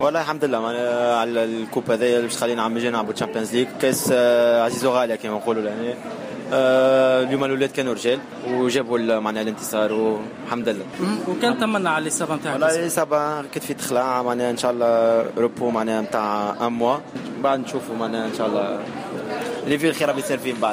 يوسف المساكني : لاعب لخويا القطري